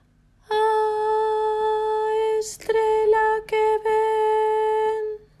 - cantaremos sobre unha mesma nota as frases do apartado anterior, seguindo a mesma orde.
Sobre a nota La